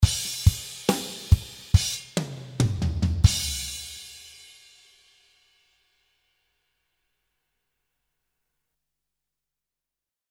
Free slow rock drum loops for all users that writes and makes music.
Classic Rock style, this product contains 30 loops with Cymbals and tom fills.
More then 10 tom fills , 10 beat loops with close hihat, 7 beat loops with ride cymbals. With very bright snare sound and even the loops are with real drummer you will find the beat very accurate.